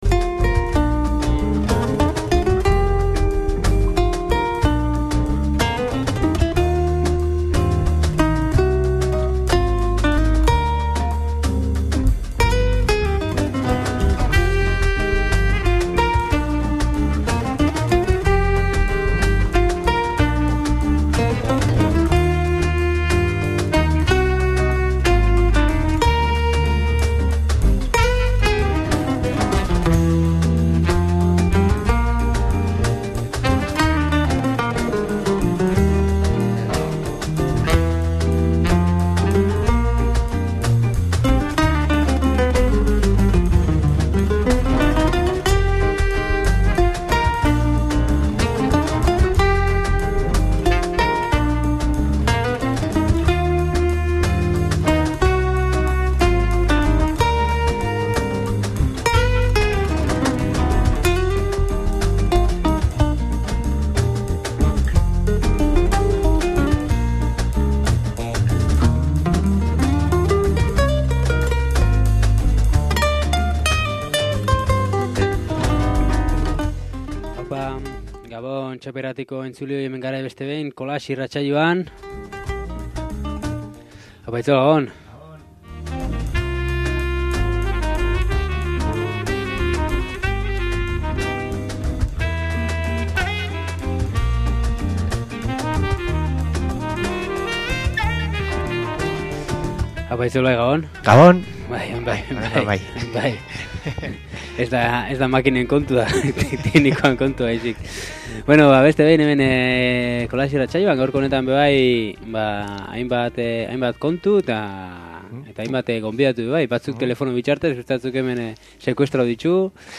Gaurko kolaxean bi gai nagusi izan ditxugu. Gure esku dago! ekimena aurkeztu dou eta Herriko taldeko bi kide izan ditxugu irratixan.